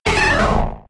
Cri de Tartard K.O. dans Pokémon X et Y.